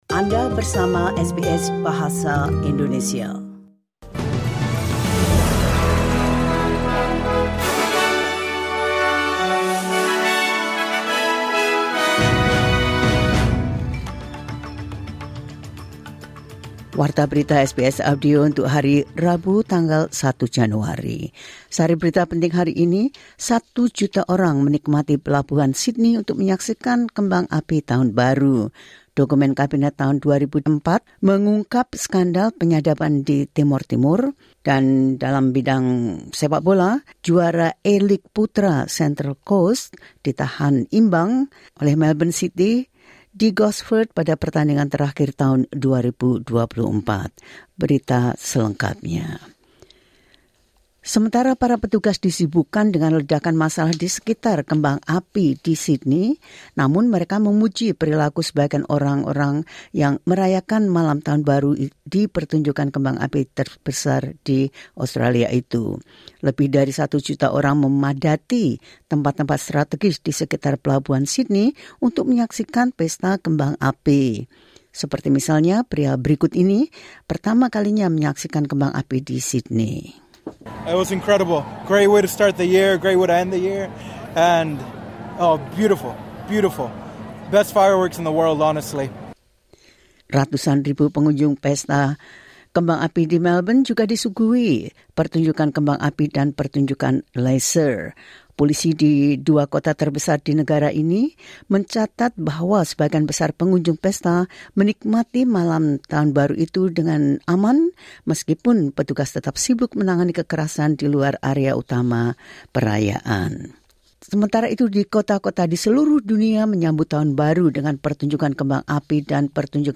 The latest news SBS Audio Indonesian Program – 01 January 2025.